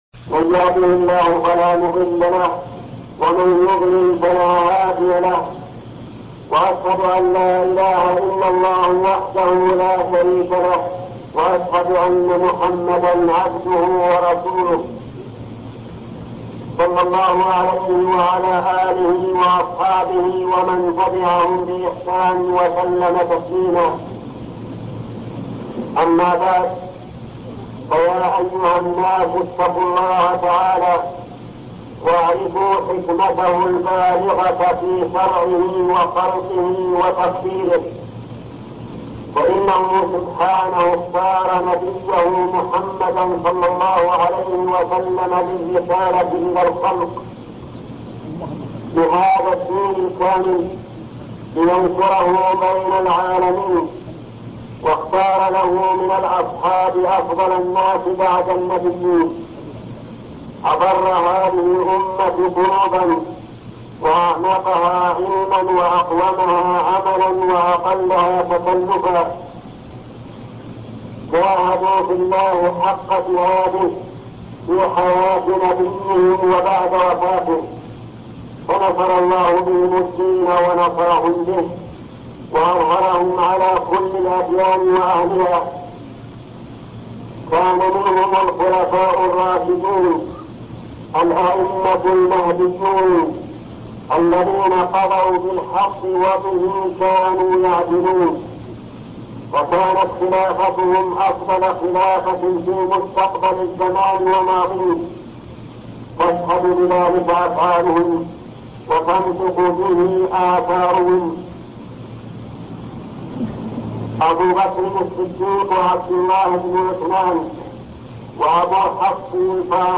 خطب الجمعة - الشيخ محمد بن صالح العثيمين